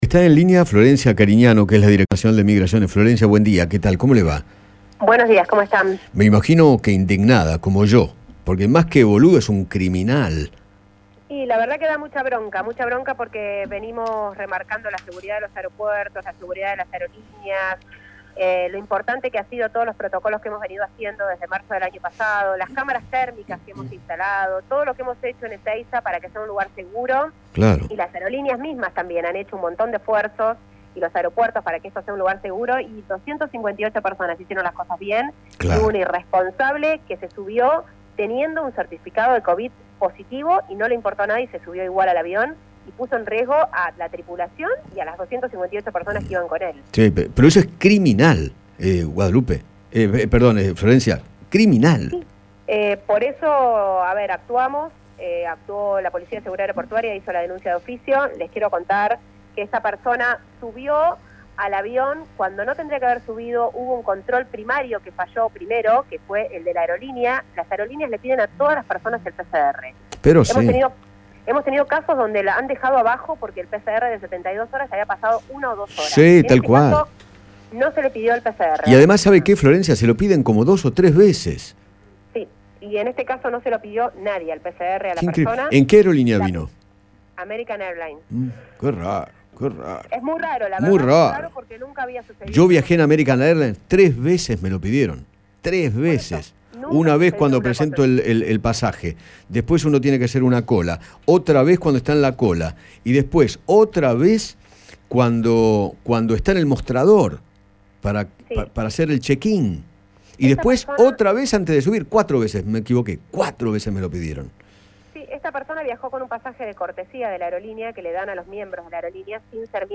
Florencia Carignano, directora nacional de Migraciones, dialogó con Eduardo Feinmann sobre lo ocurrido con el hombre que volvió de Miami y expresó su bronca contra el irresponsable.